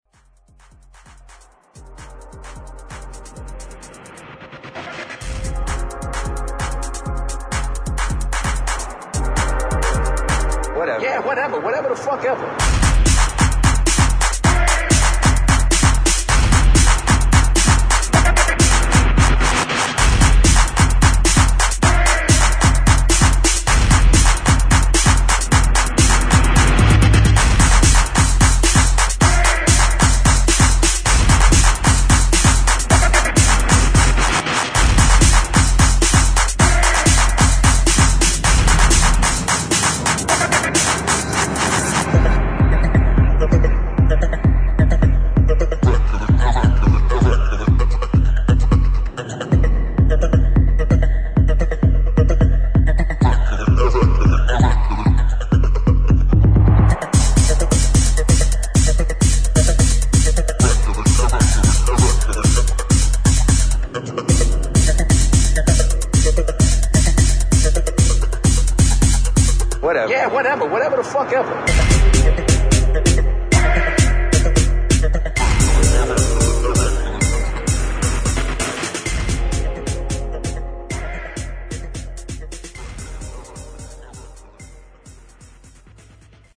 [ BASS / TECHNO / ELECTRONIC ]